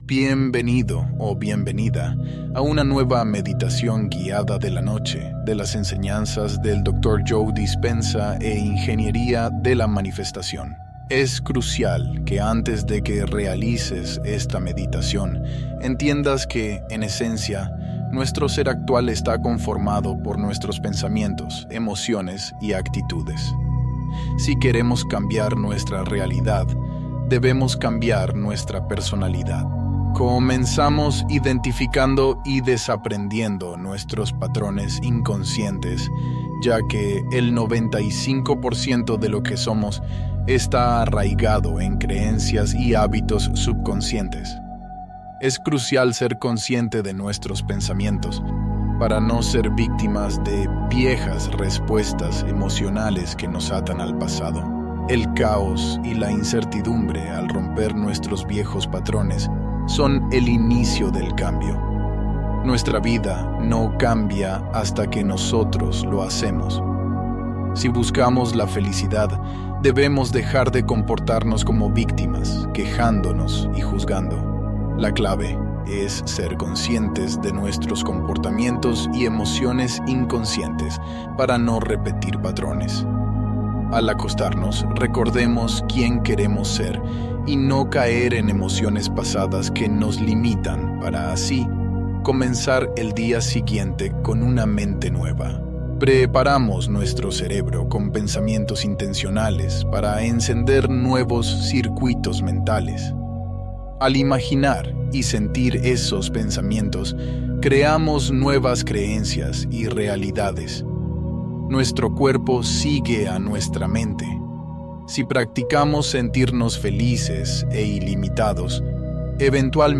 Reprograma el Subconsciente Mientras Duermes | Afirmaciones